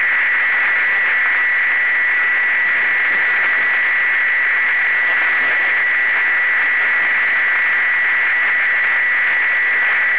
Сигналы на 20-ке